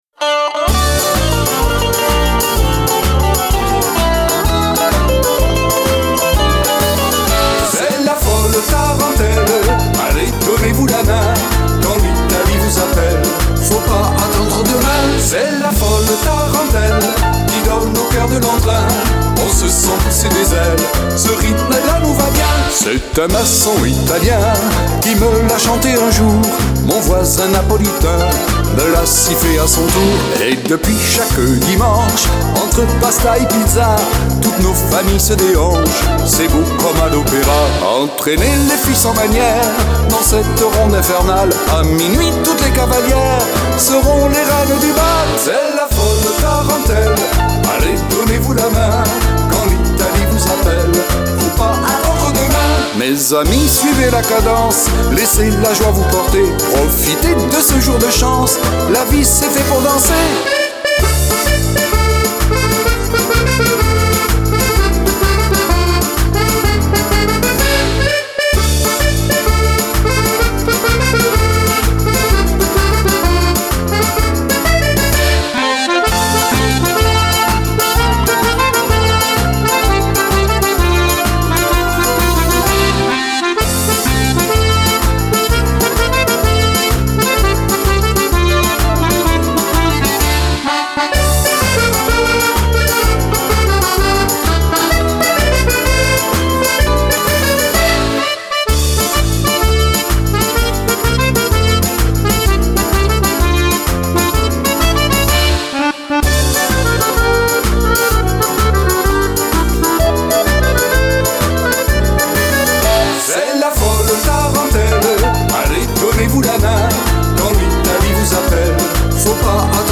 version chantée par moi même
accordéon